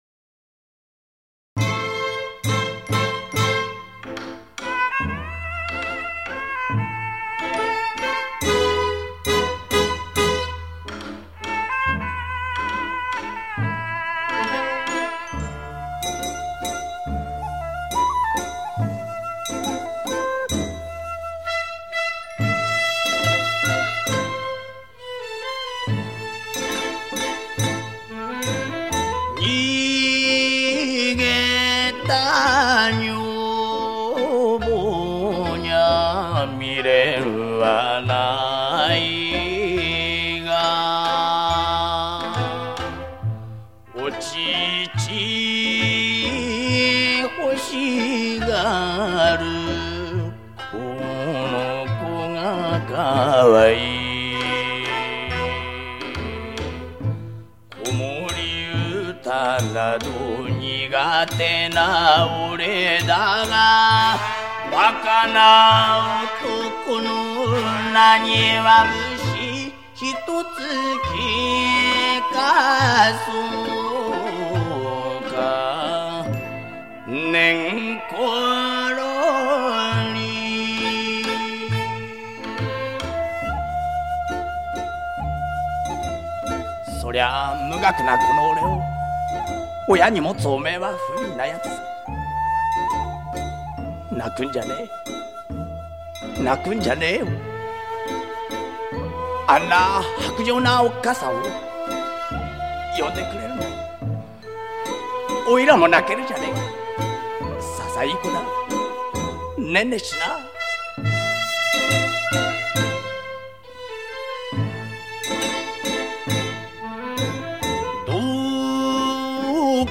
他将传统民间歌谣和现代流行曲的唱法熔为一炉，形成了自己独特的演唱技法。